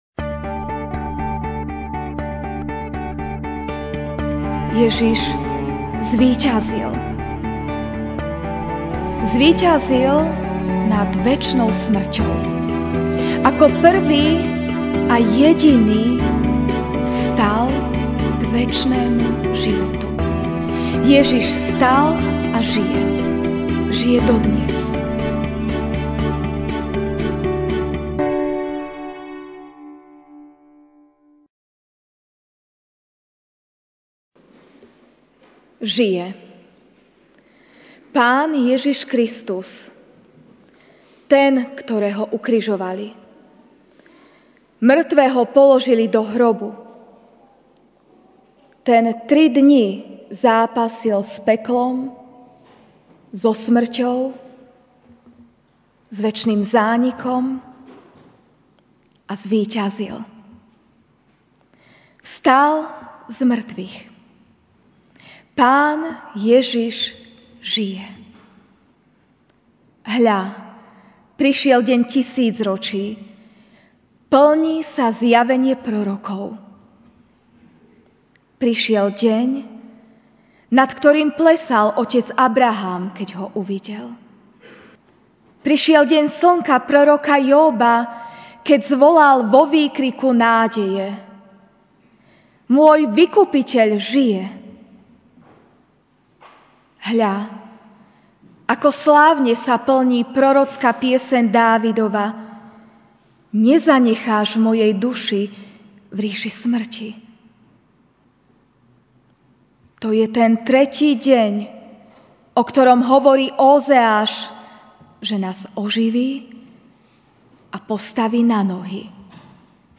MP3 SUBSCRIBE on iTunes(Podcast) Notes Sermons in this Series Ranná kázeň: Ježiš žije!